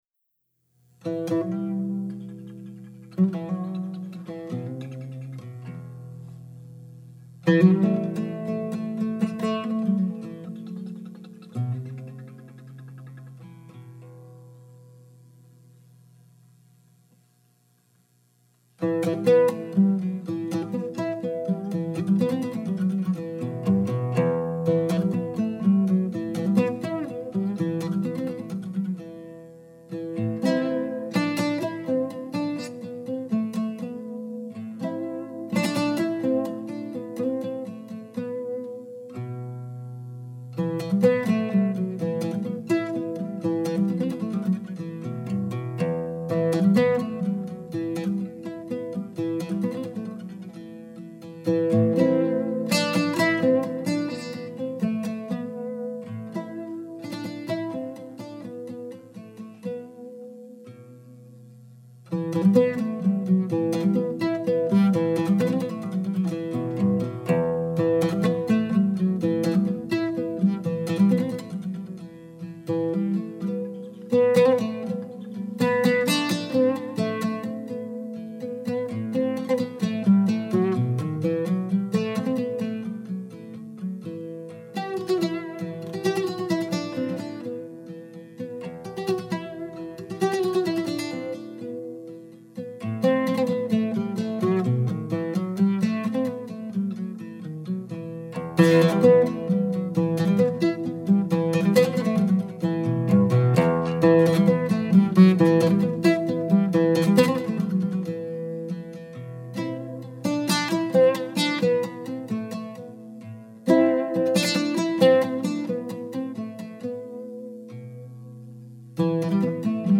Oud solo recorded live in Durham NH 11/17/06
a solo oud rendition
Armenian melody
recorded live at the Mill Pond Center for the Arts.